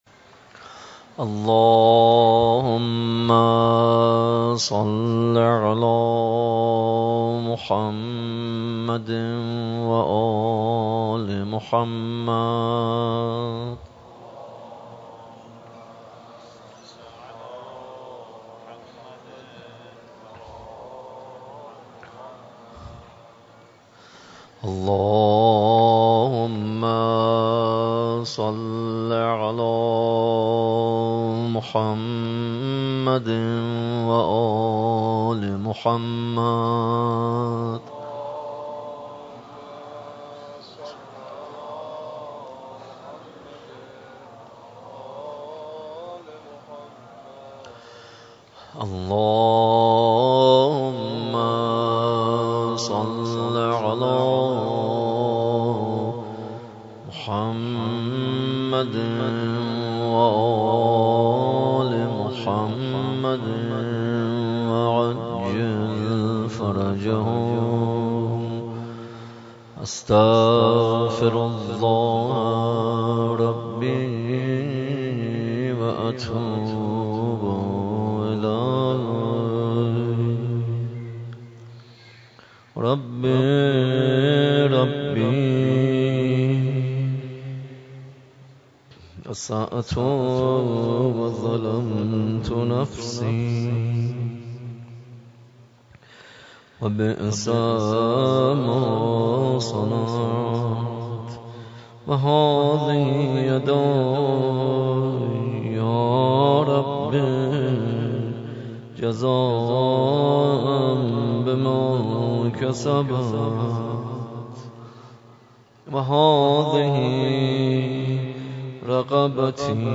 در مسجد امام حسین (ع) واقع در میدان امام حسین(ع) برگزار گردید.
دعای کمیل